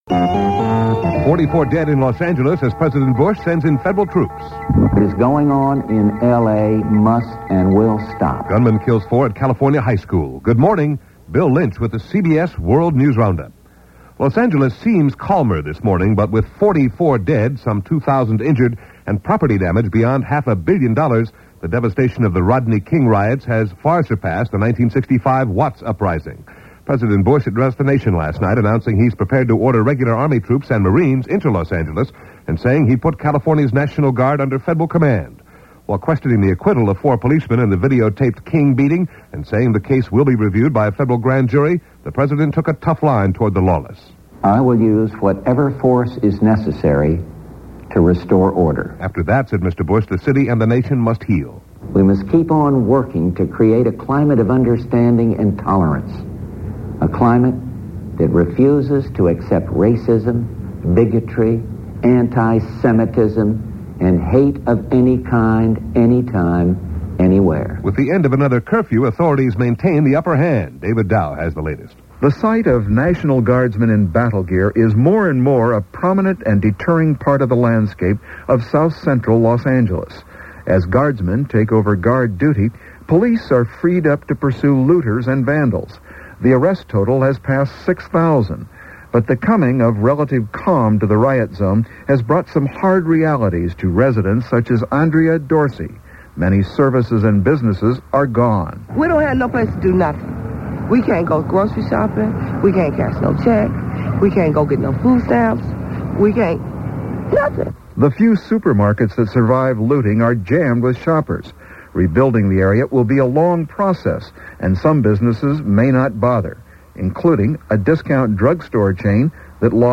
And that’s just a small slice of what went on in this fractured world of ours, May 2, 1992 as reported by the CBS World News Roundup.